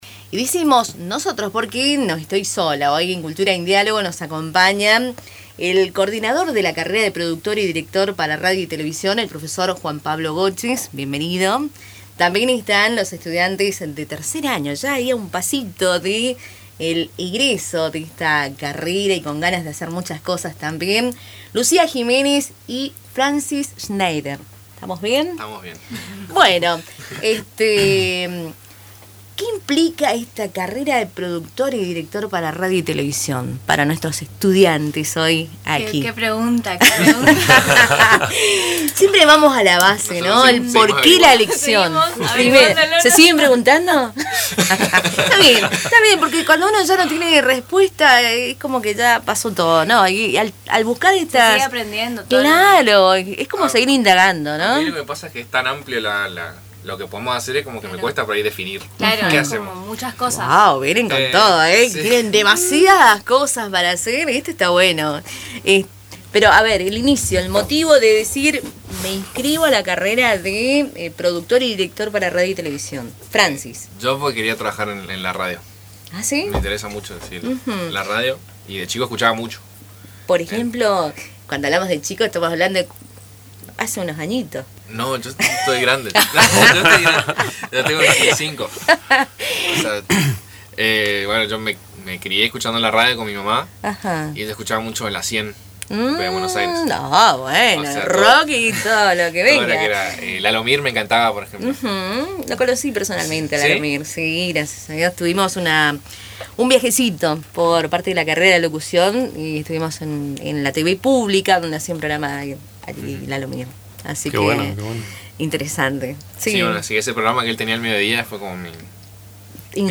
Durante la entrevista, los alumnos relataron cómo nació su interés por los medios.